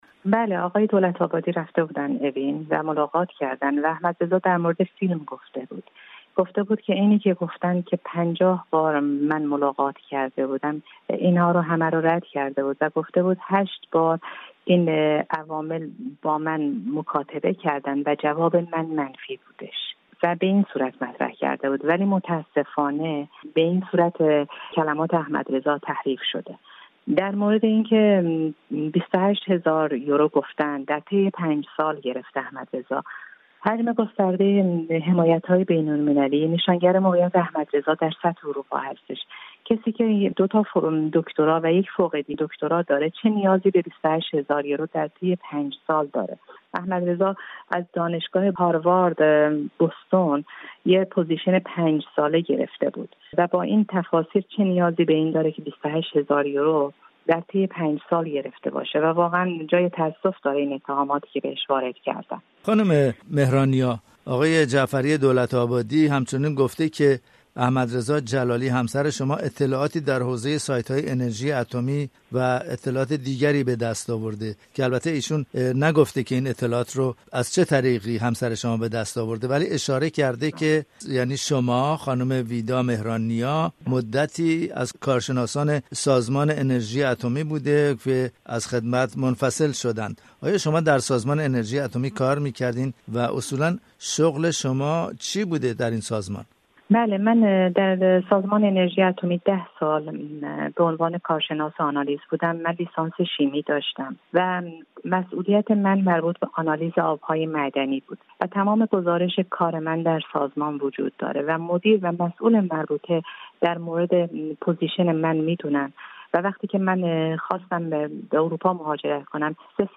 در گفت‌وگو با رادیو فردا به این اظهارات پاسخ داده است.